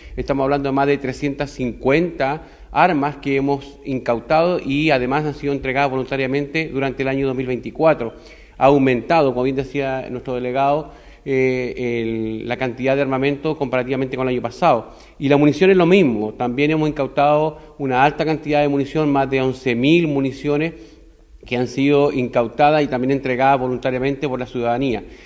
Según las autoridades, entre comiso y entrega voluntaria de armas y de municiones, este 2024 hubo un aumento en relación con el año pasado, esto de un 42,6% y 78,2%, respectivamente, números que explicó el jefe de la Décimo Cuarta Zona Los Ríos, general Patricio Faunes.